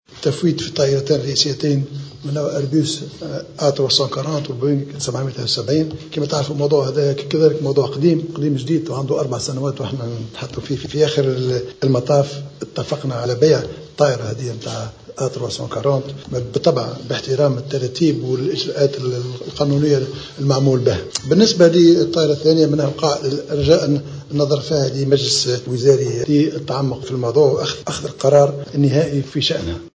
قال رئيس الحكومة الحبيب الصيد في تصريح اعلامي اليوم الأربعاء 18 فيفري 2015 إنه تم الاتفاق على التفويت في الطائرة الرئاسية ايرباص A340 باحترام التراتيب والإجراءات القانونية المعمول بها في مثل هذه الصفقات وإرجاء التفويت في الطائرة الثانية للنظر فيه في مجلس وزاري من أجل مزيد التعمق في الموضوع وأخذ قرار نهائي في شأنها وفق قوله.